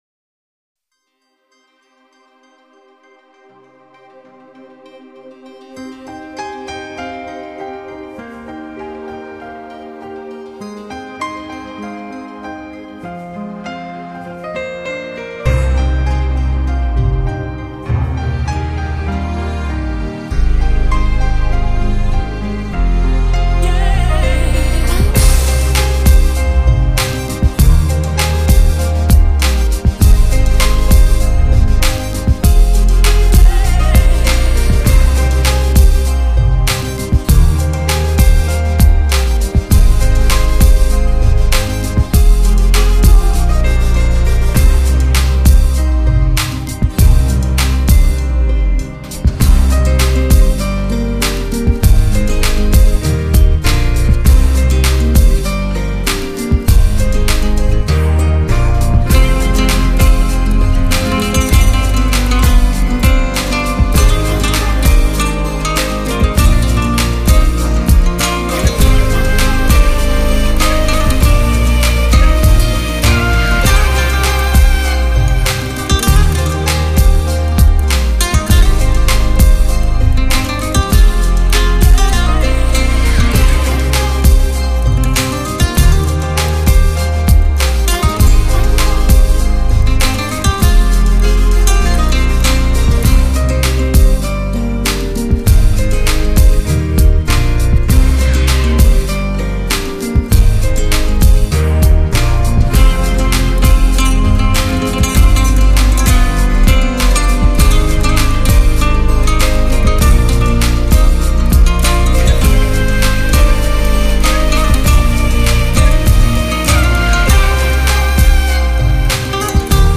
集结来自国际的15人管弦乐团幕后伴奏，融合流行节奏
照亮光明人生，渴望更多更美好事物，广泛运用古典、流行、弗拉明戈、电子等元素融合